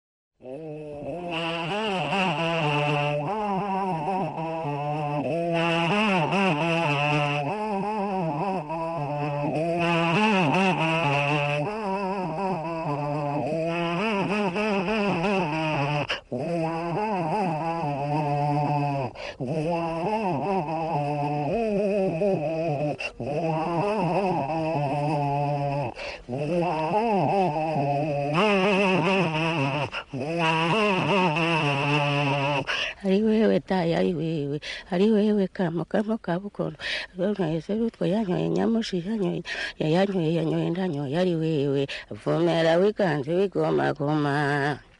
Ce track, pour commencer, a toujours eu une drôle d’influence sur moi, cette voix étouffée, nasillarde, chuchotée est plus flippante que n’importe quel Skinny Puppy.
Ambiance poissarde garantie.